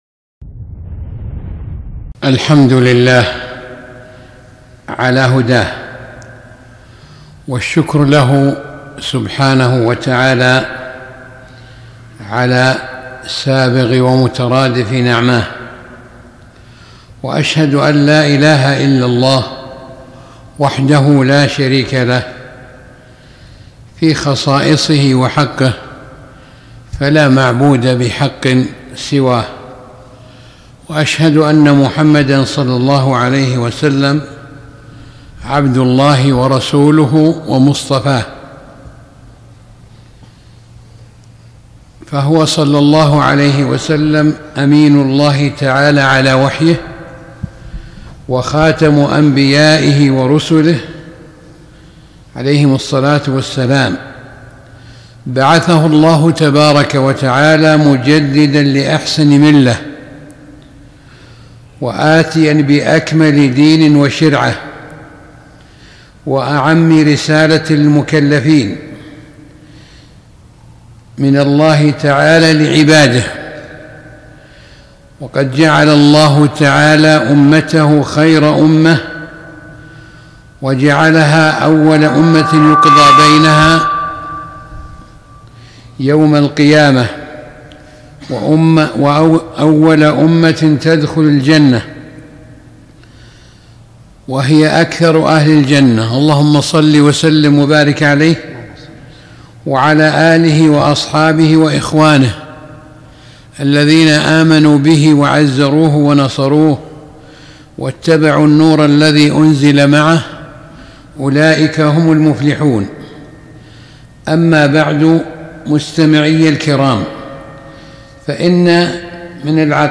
محاضرة - فضل الفرقة الناجية والتحذير من الأحزاب الضالة